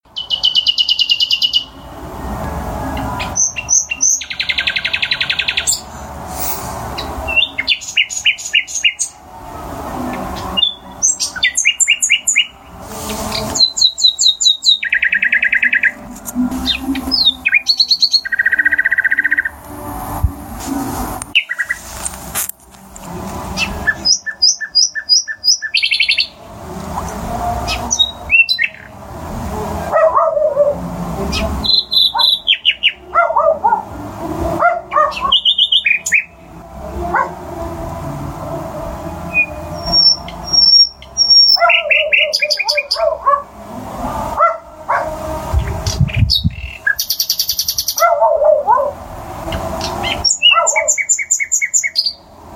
reiseñor
canto reiseñor